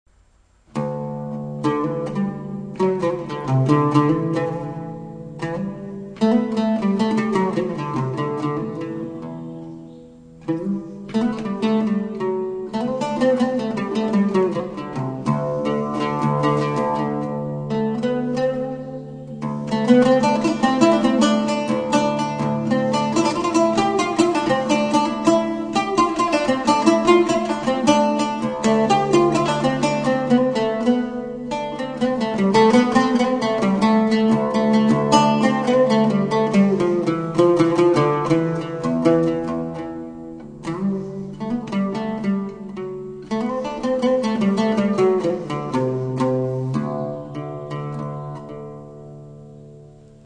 LUTH
ENSEMBLE PERCEVAL